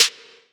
DDK1 SNARE 9.wav